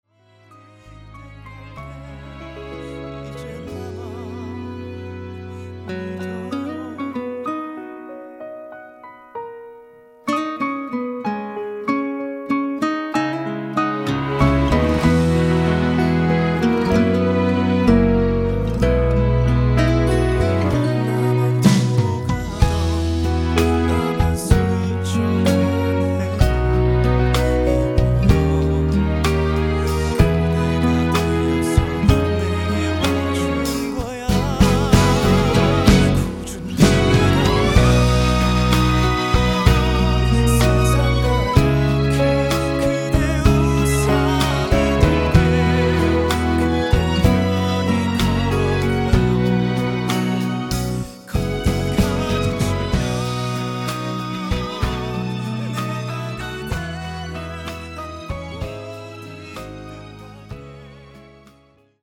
음정 원키 4:03
장르 가요 구분 Voice Cut